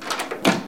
Исправить звук дверей: ... - преобразовать звук в стерео; - уменьшить уровень звука